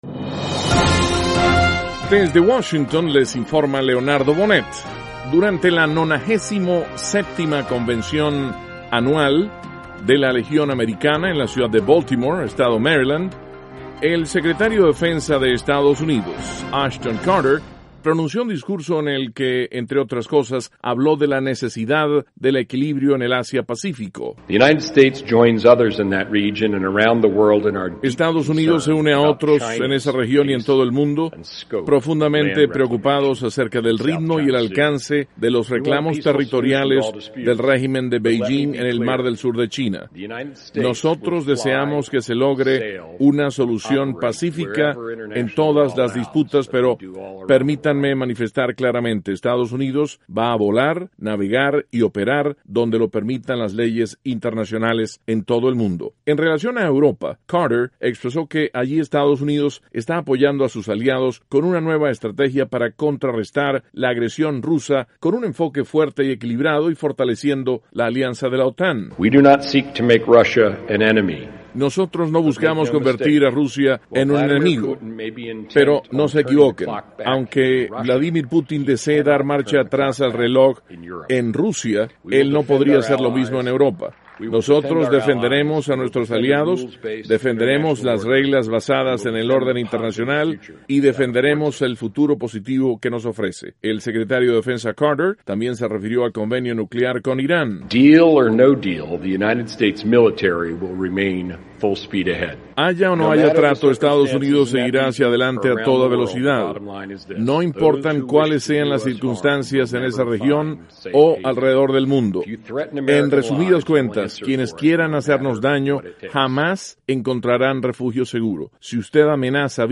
El secretario de Defensa de Estados Unidos, Ashton Carter, habla en un discurso sobre la necesidad de equilibrio en la región Asia Pacífico, el apoyo a aliados Europeos frente a agresión de Rusia y sobre el acuerdo nuclear con Irán.